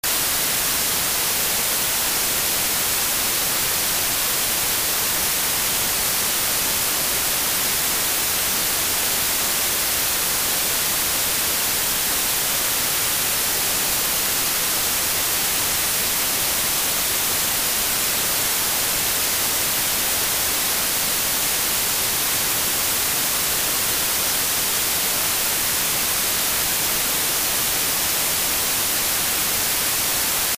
Пуэрильное дыхание правое легкое звук